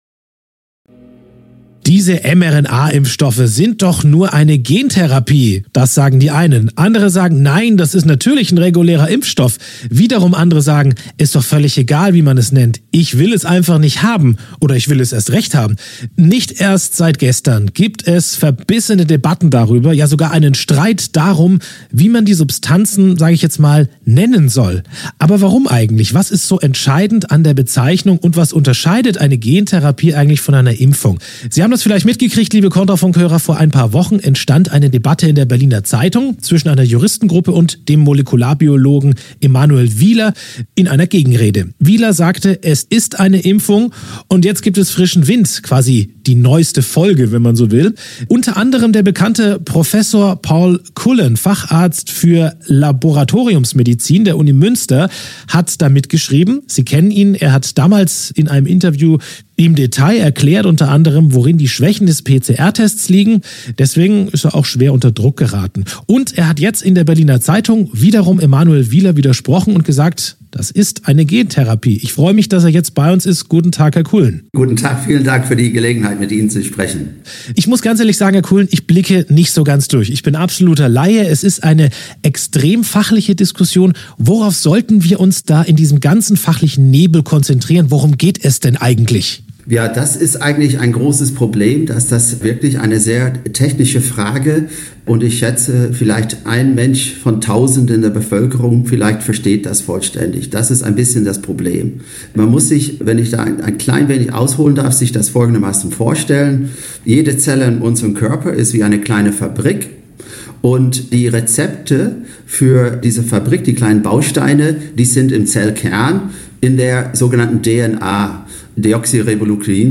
… Interview vom 27.3.2023 eine Antwort